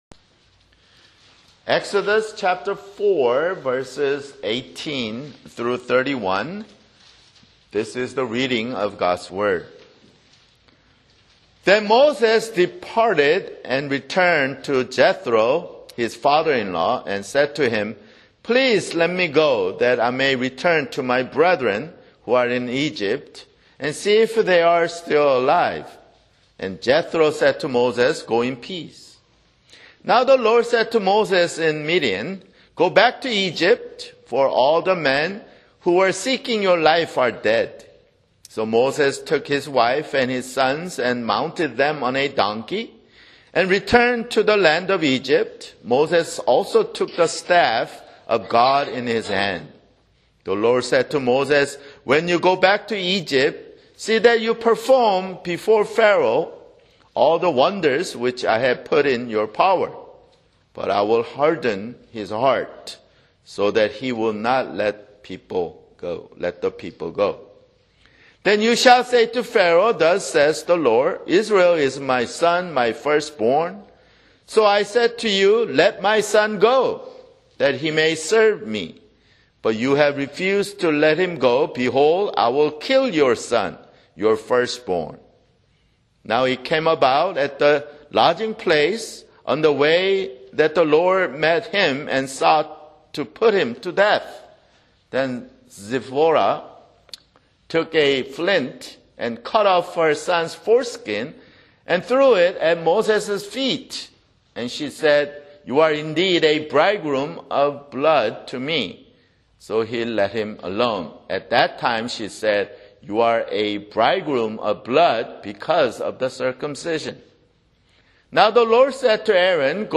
[Sermon] Exodus (10)